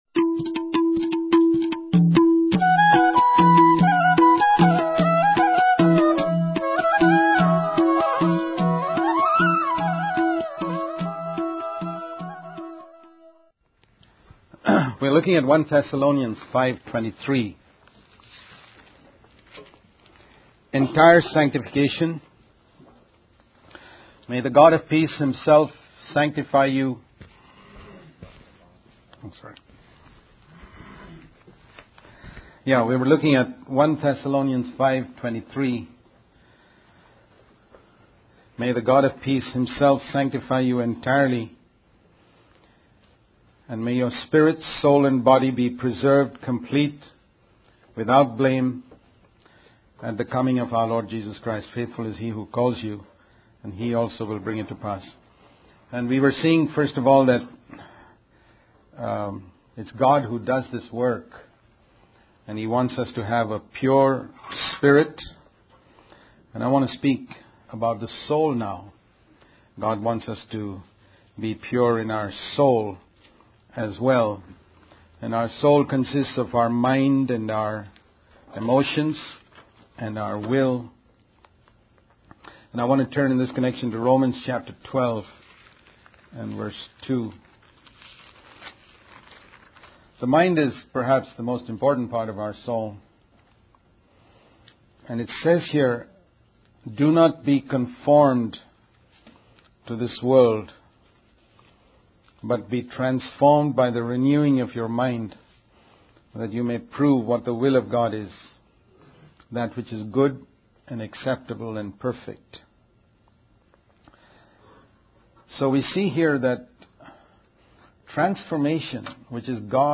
In this sermon, the speaker focuses on the importance of renewing our minds in order to be transformed into the likeness of Christ. The mind is emphasized as a crucial part of our soul, even more important than our emotions.